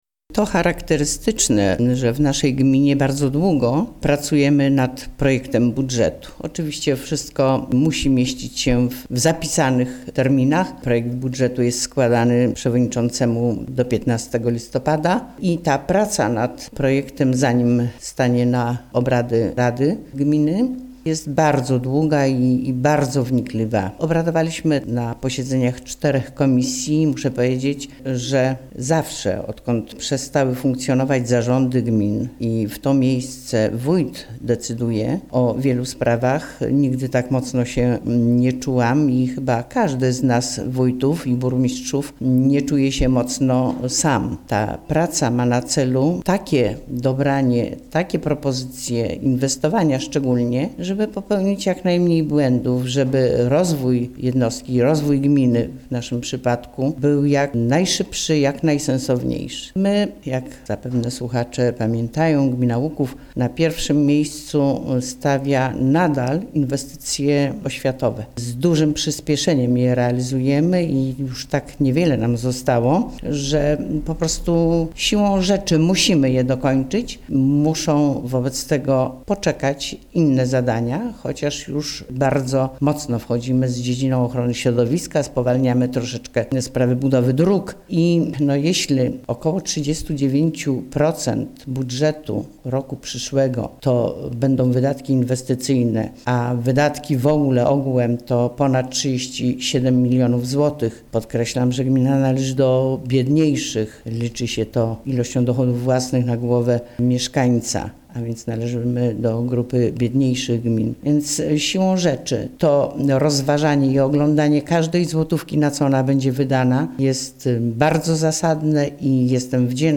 Wójt Gminy Łuków